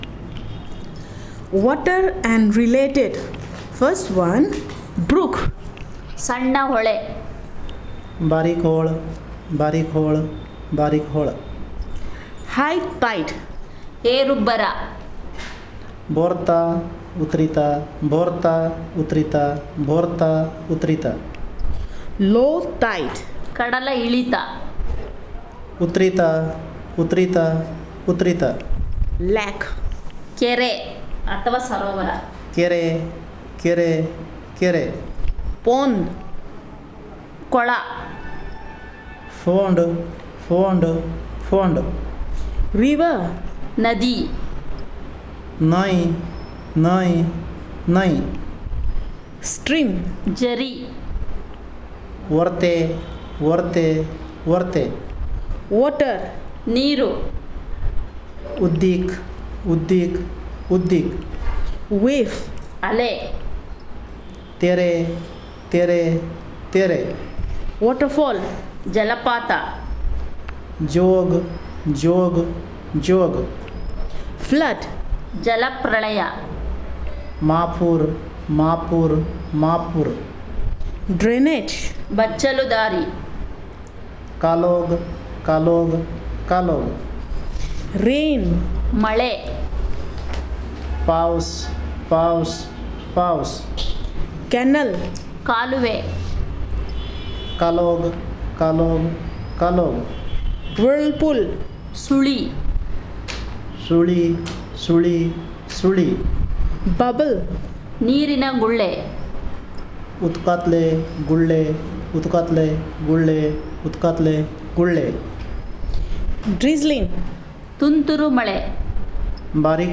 Elicitation of words about water and related